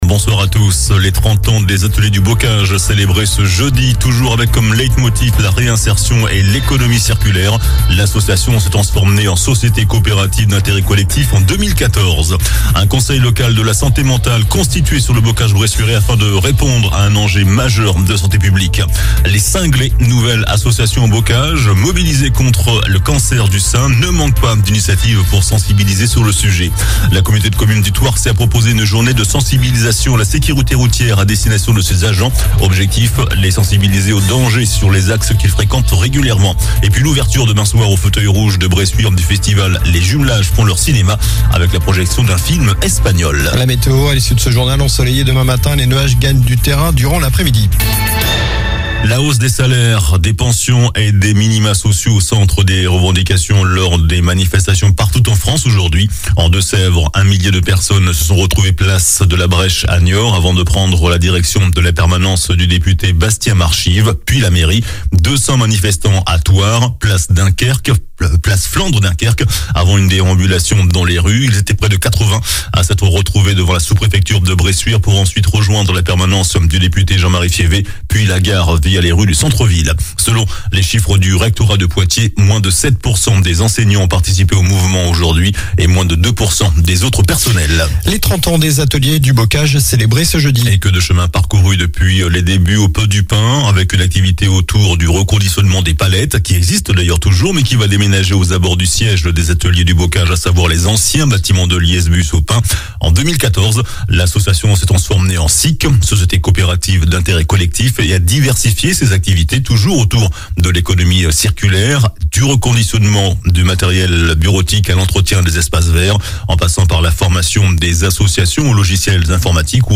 JOURNAL DU JEUDI 29 SEPTEMBRE ( SOIR )